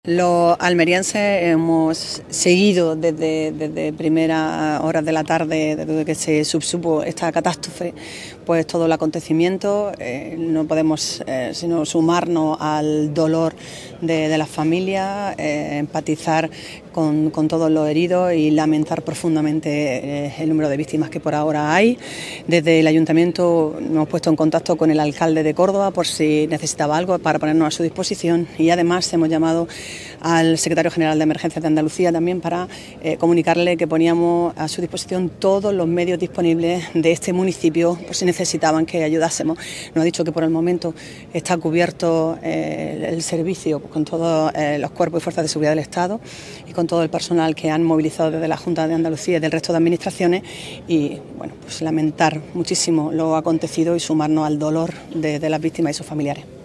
Un acto celebrado a mediodía en la Plaza de la Constitución que ha estado presidido por la alcaldesa de Almería, María del Mar Vázquez, y el consejero de Agricultura, Pesca, Agua y Desarrollo Rural de la Junta de Andalucía, Ramón Fernández-Pacheco, quienes han estado acompañados del Equipo de Gobierno, representantes de los grupos municipales y de otras administraciones públicas como la Junta de Andalucía y senadores así como de funcionarios municipales, Policía Local, Bomberos de Almería, Policía Nacional y representantes de la Audiencia Provincial.
ALCALDESA-MINUTO-SILENCIO-ACCIDENTE-FERROVIARIO.mp3